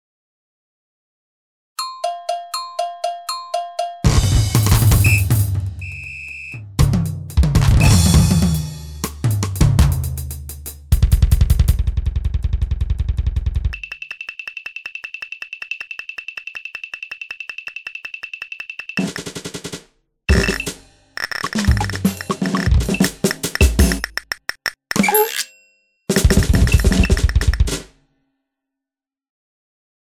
schnell